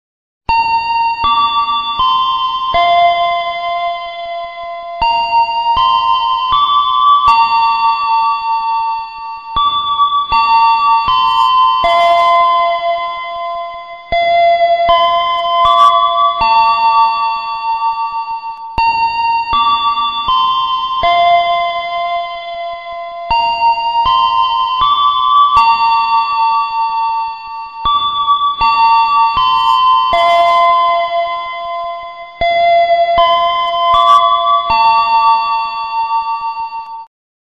Nada Dering Suara Bel Stasiun Kereta Api
Keterangan: Ringtone Suara Bel Stasiun Kereta Api Indonesia MP3 adalah suara bel stasiun yang khas, sering terdengar saat kereta akan berangkat atau tiba.
nada-dering-suara-bel-stasiun-kereta-api-id-www_tiengdong_com.mp3